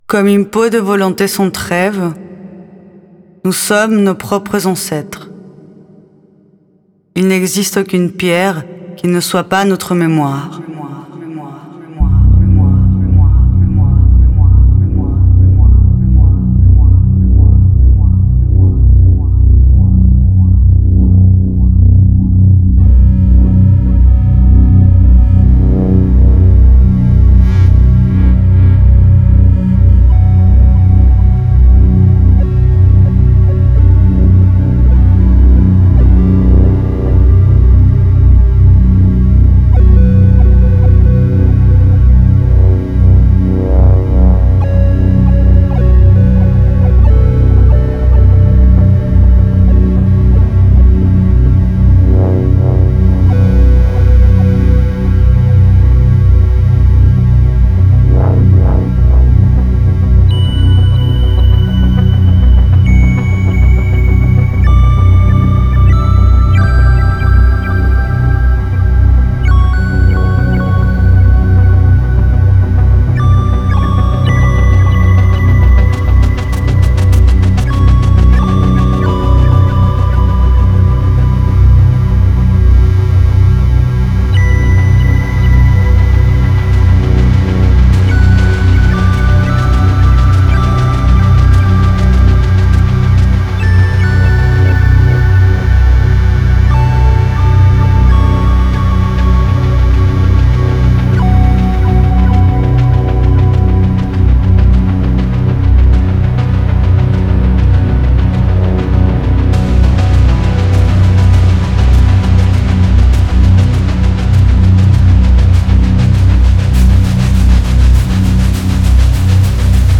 Elle a également enregistré certaines voix du lavoir, que l’on peut écouter via des QR Codes auprès de l’œuvre ou ci-dessous :